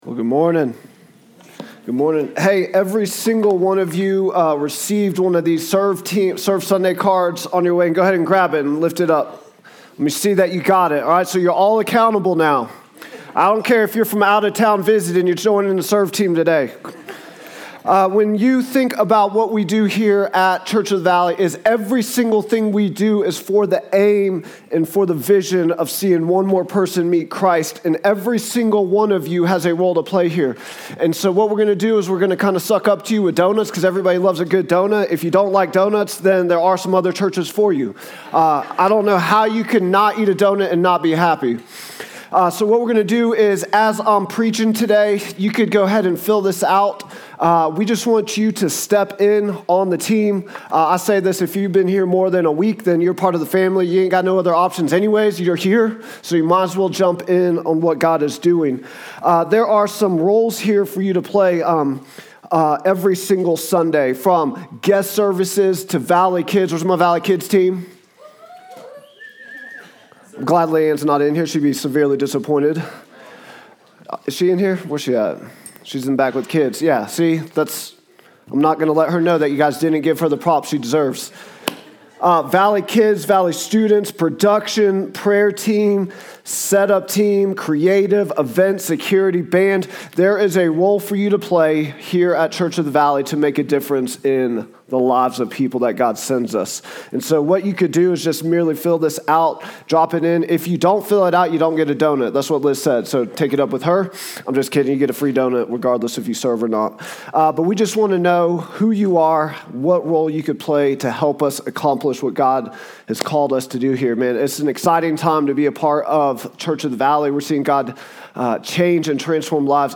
Vision & Values Meet Our Team Statement of Faith Sermons Contact Us Give To the Ends of the Earth | Acts 15:1-18 March 1, 2026